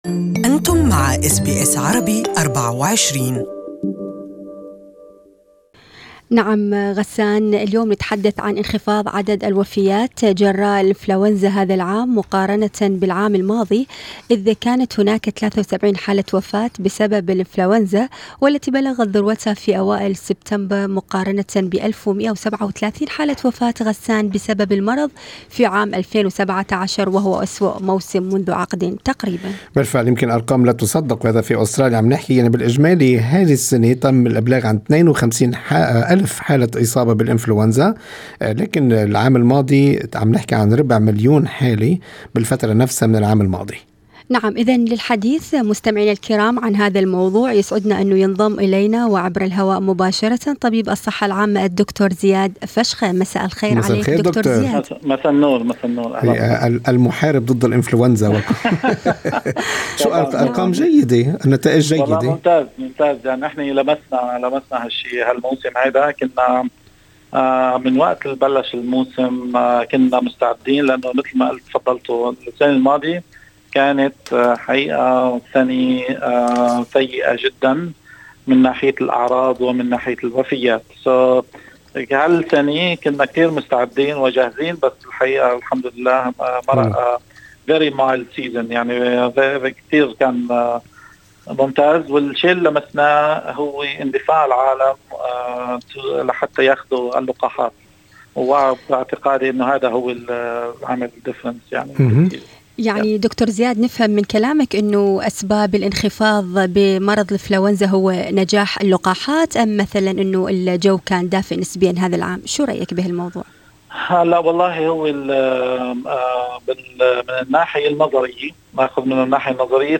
This interview is available in Arabic